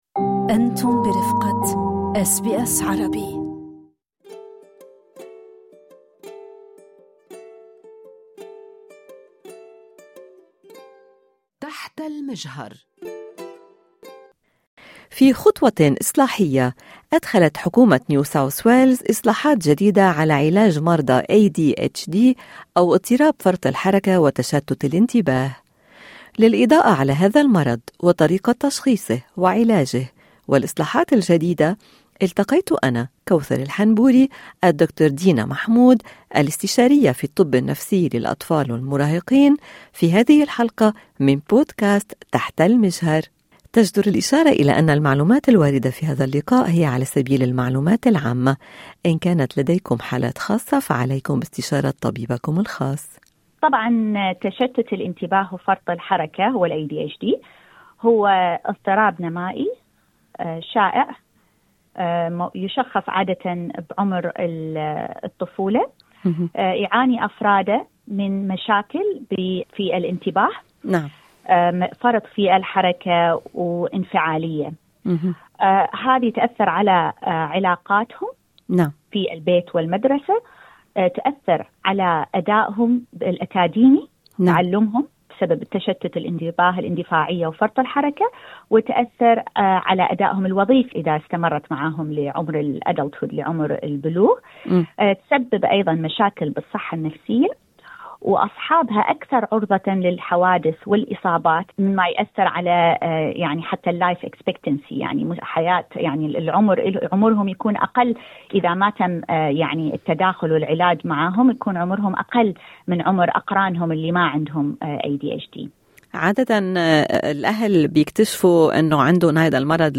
إشارة الى أن هذا اللقاء يقدم معلومات عامة فقط لمزيد من التفاصيل عن حالات خاصة عليكم باستشارة طبيبكم الخاص